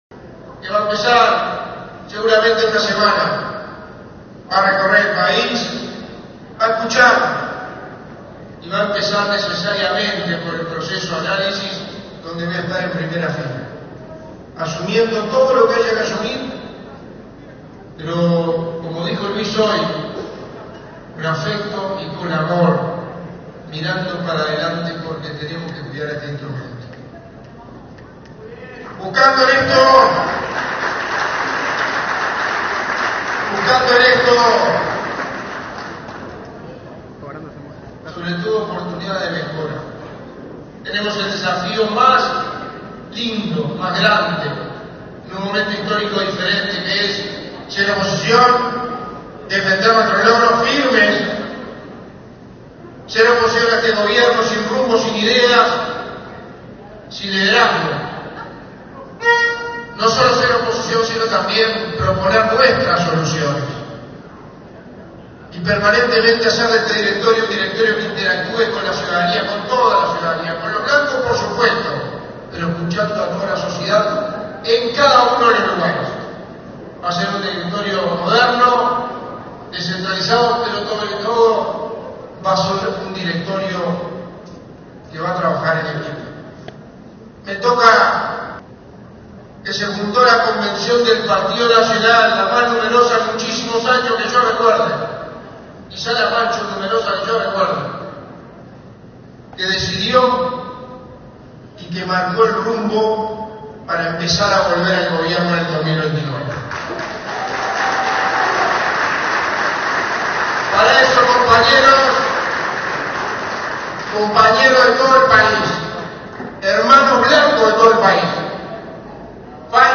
El Partido Nacional celebró este sábado su Convención Nacional en Montevideo, instancia en la que se definió la integración del nuevo Directorio de la colectividad.
Durante su intervención, Delgado valoró el respaldo recibido en la interna blanca y afirmó que el partido se prepara para los desafíos del ciclo electoral.